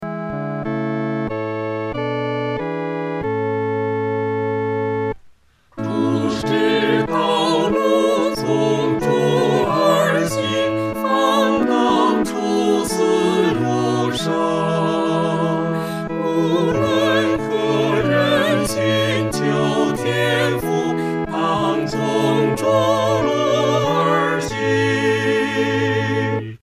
四声
本首圣诗由石家庄圣诗班录制